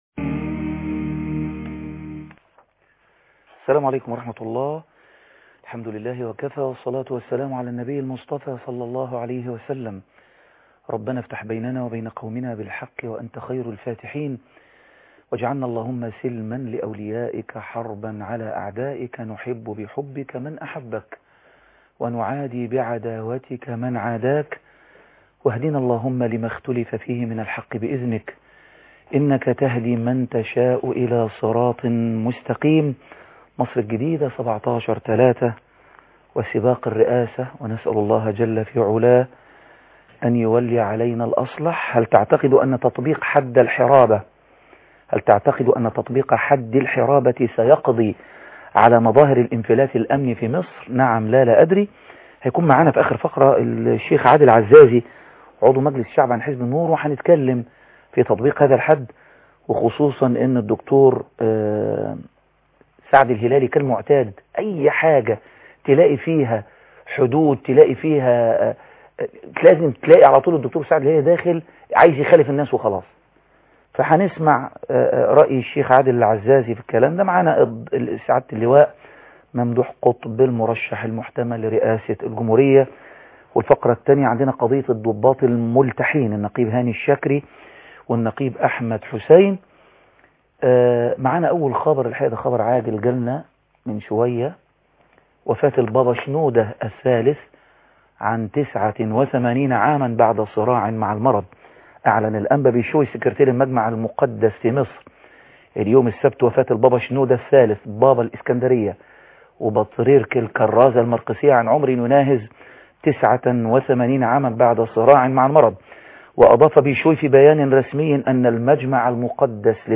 لقاء خاص